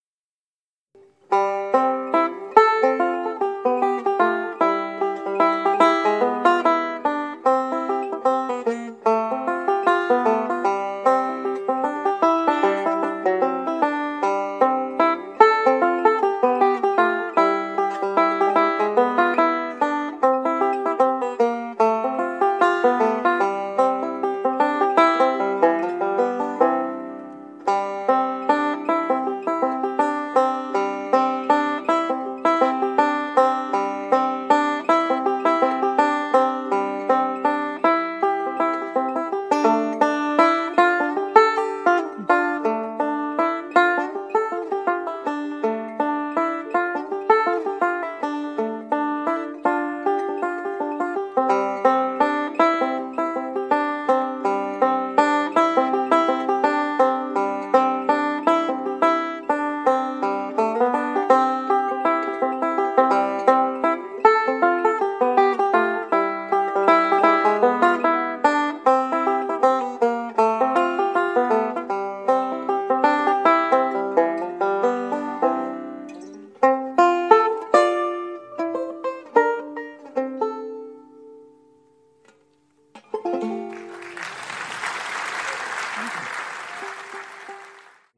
8-beat intro.
This song is in the key of F.
5-string banjo